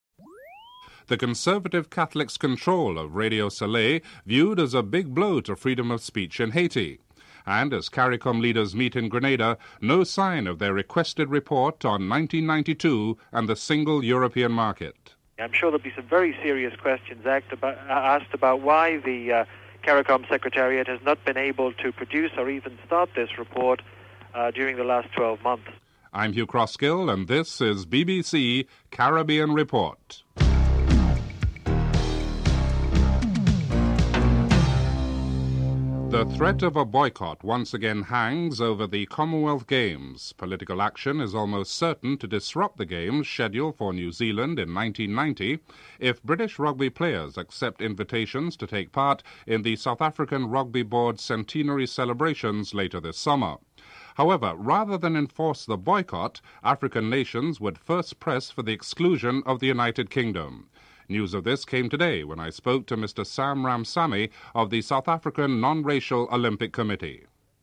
1. Headlines (00:00-00:29)
4. Financial news (04:11-05:42)